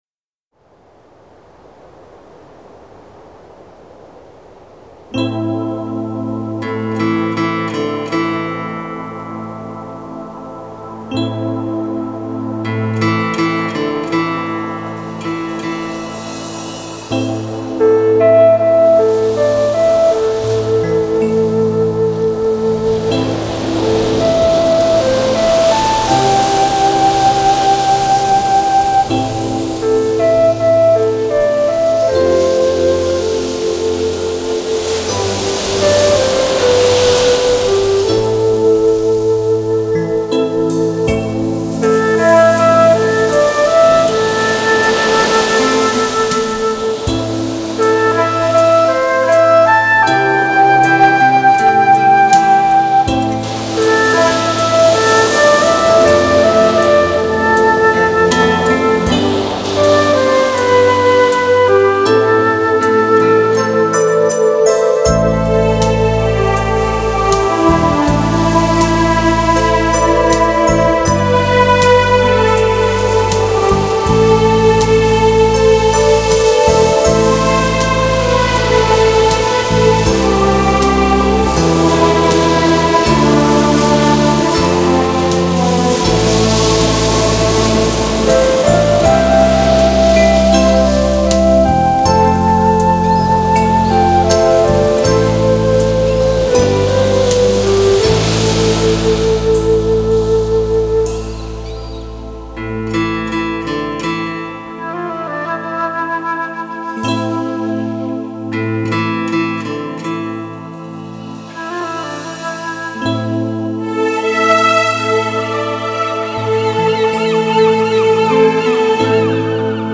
集韩国音乐之精华，应用世界音乐风格的编曲，加上南朝鲜国宝级的民族器乐演奏家